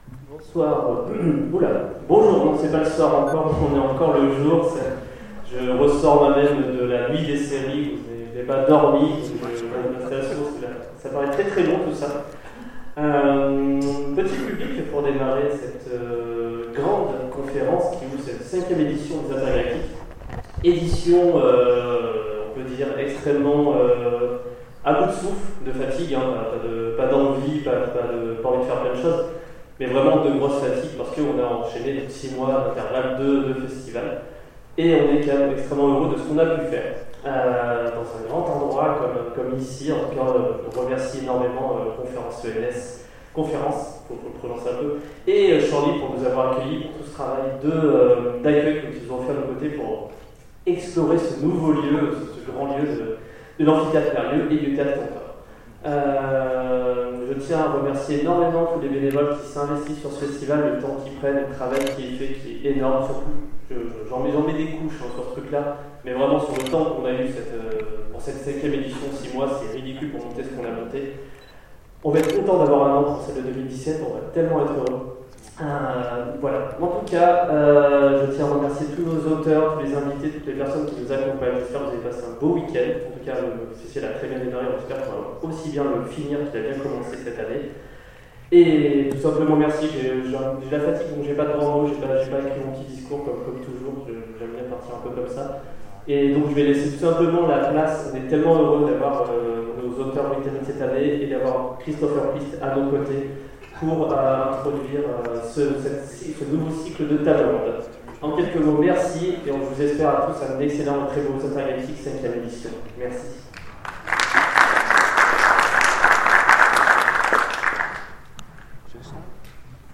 Intergalactiques 2016 : Conférence d'ouverture